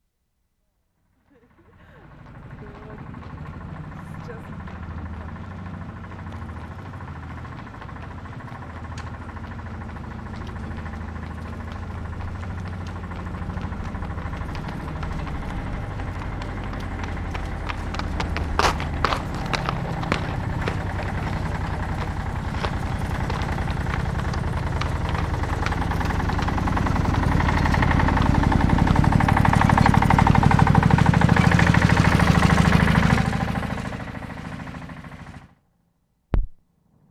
Bissingen, Germany March 6/75
TRACTOR, driving down Mittlere Strasse